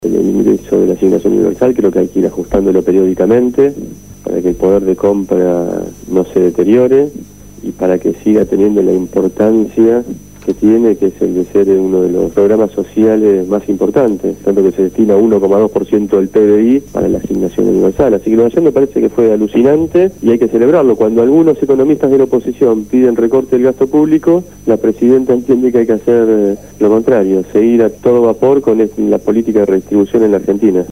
Ariel Pasini se refirió en esta entrevista a la Asignación Universal por Hijo, las jubilaciones, el Banco del Sur, la Ley de Tierras y la economía argentina.